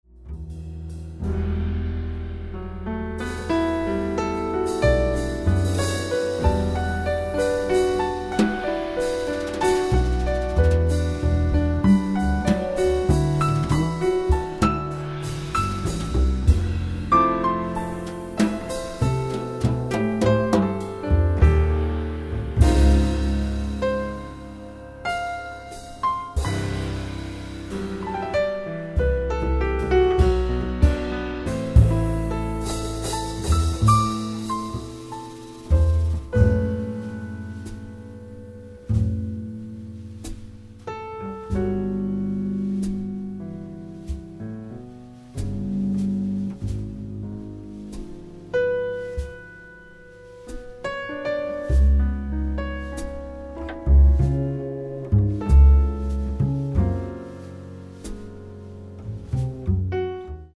piano trio